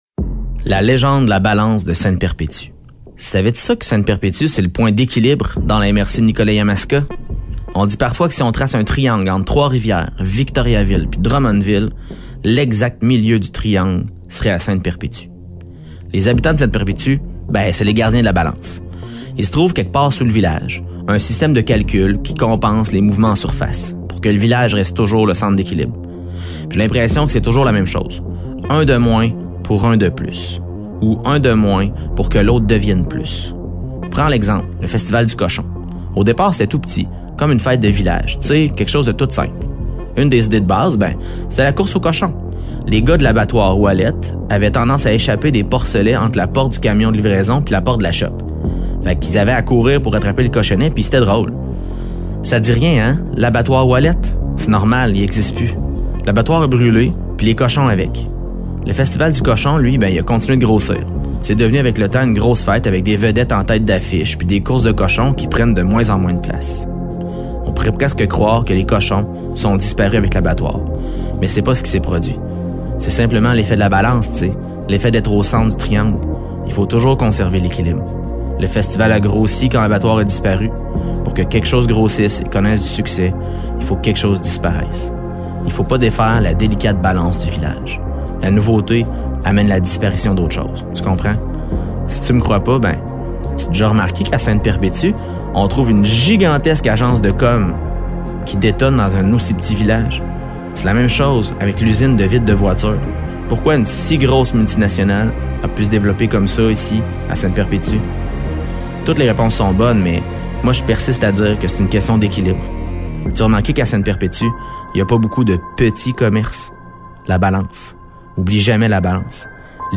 Ce conte fait partie d’un projet initié par la MRC de Nicolet-Yamaska dans le cadre de son Entente de développement culturel avec le ministère de la Culture et des Communications et réalisé de concert avec VIA 90.5 FM.